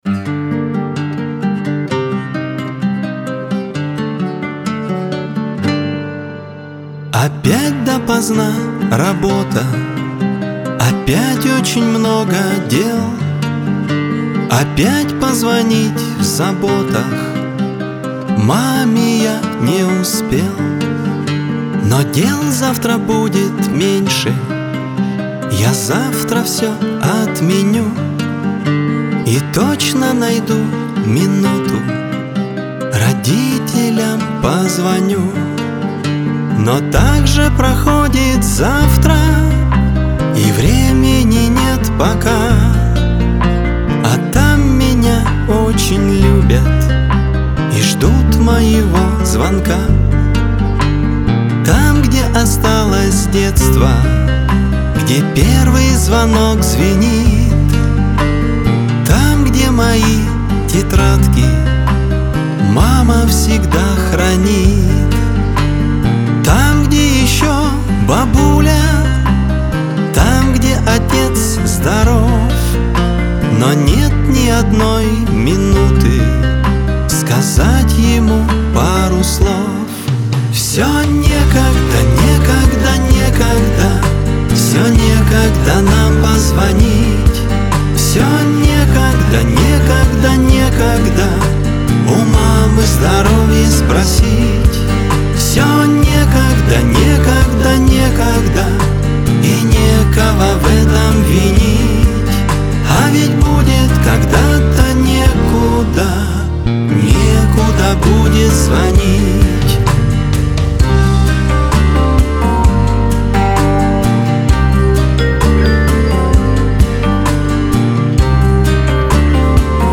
эстрада
Лирика , Шансон